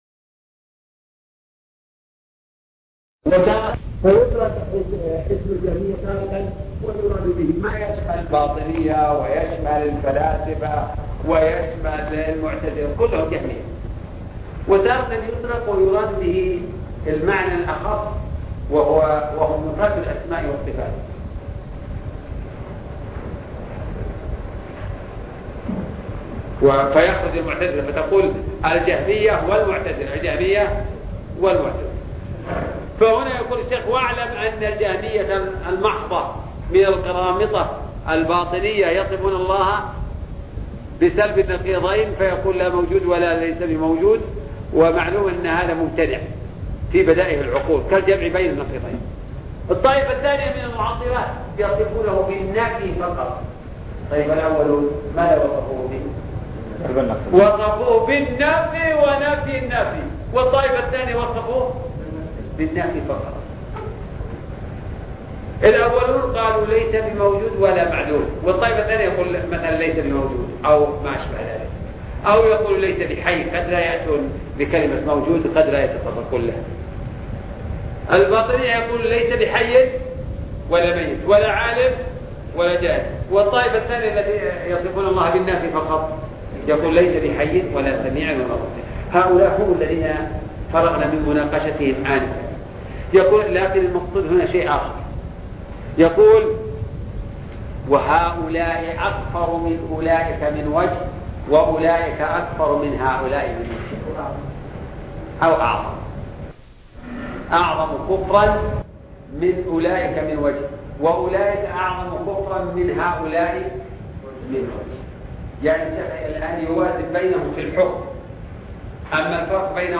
الدرس (18) شرح التدمرية - الشيخ عبد الرحمن بن ناصر البراك